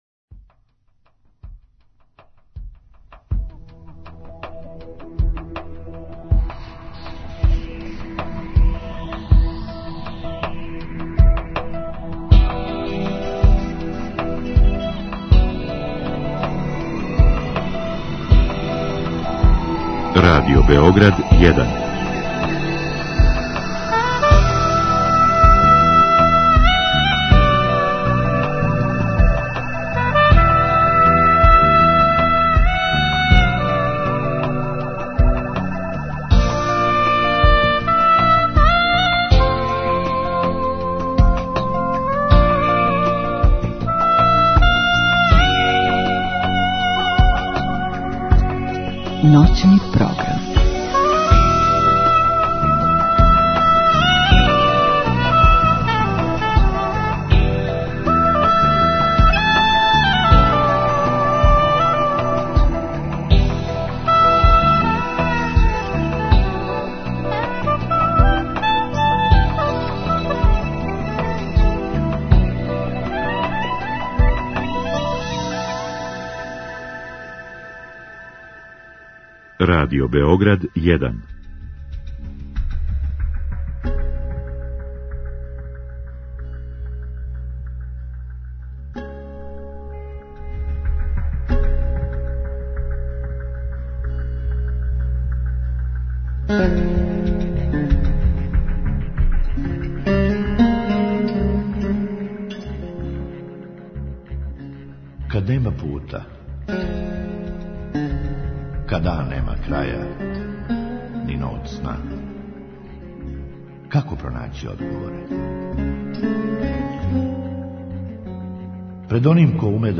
Други сат предвиђен је за укључење слушалаца, који у директном програму могу поставити питање госту.